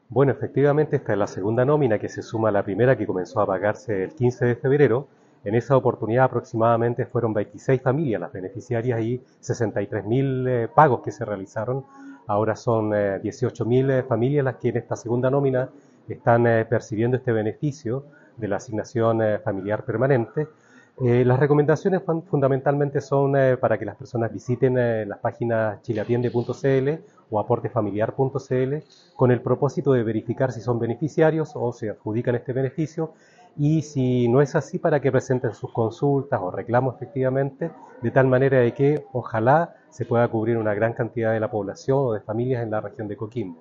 Al respecto el Seremi del Trabajo y Previsión Social, Francisco Brizuela, explicó que
Seremi-del-Trabajo-07-03.mp3